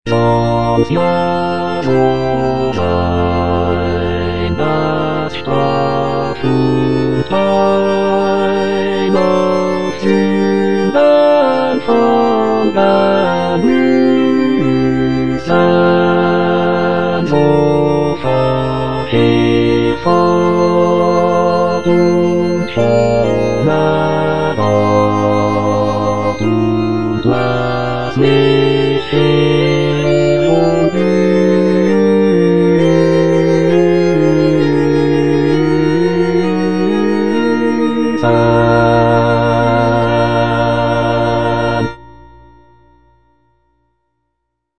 Cantata
Bass (Emphasised voice and other voices) Ads stop